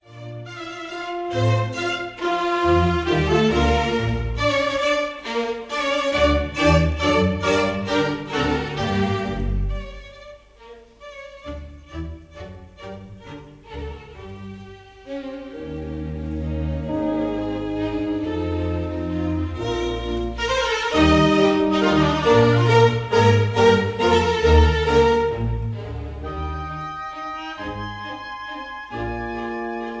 Recorderd live at the 1st Aldeburgh
Jubilee Hall, Aldeburgh, Suffolk